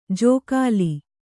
♪ jōkāli